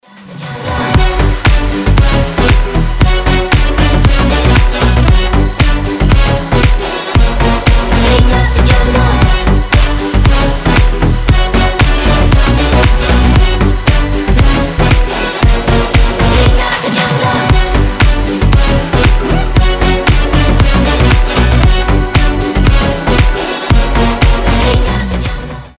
• Качество: 320, Stereo
ритмичные
громкие
веселые
заводные
future house
Веселый рингтончик - финал трека